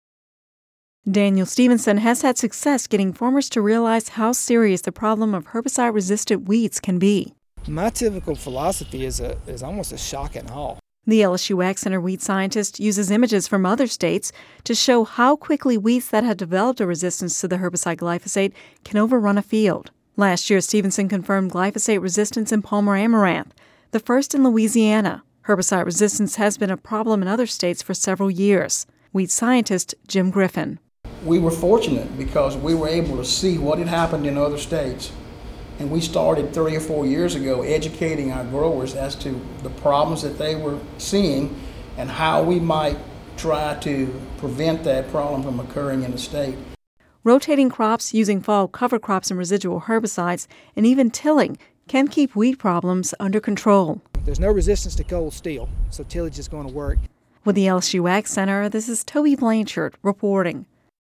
Radio News 03/28/11